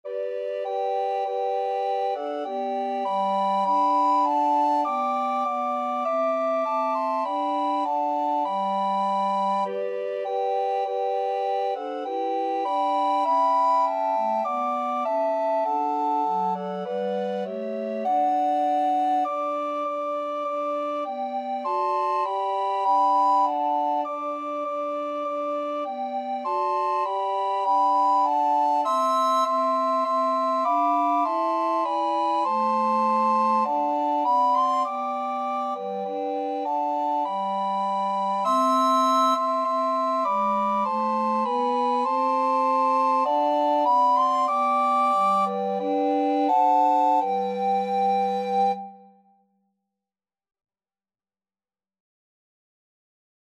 Christmas Christmas Recorder Quartet Sheet Music Hark the Herald Angels Sing
Free Sheet music for Recorder Quartet
Soprano RecorderAlto RecorderTenor RecorderBass Recorder
4/4 (View more 4/4 Music)
G major (Sounding Pitch) (View more G major Music for Recorder Quartet )
Recorder Quartet  (View more Easy Recorder Quartet Music)
Classical (View more Classical Recorder Quartet Music)